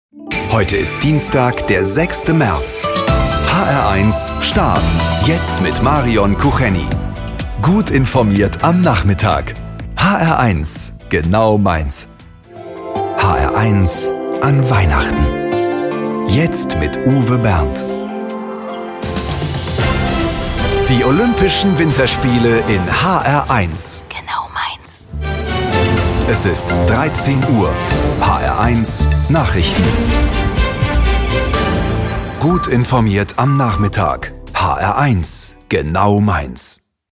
deutscher Profi - Sprecher.
Sprechprobe: Sonstiges (Muttersprache):
In one line I can describe my voice as: male, sympathetic and very flexible.